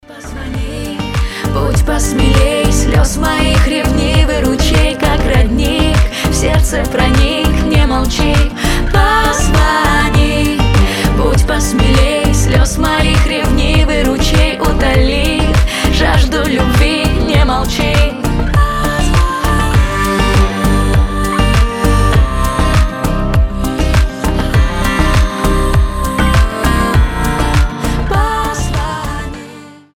• Качество: 320, Stereo
гитара
мелодичные
женский голос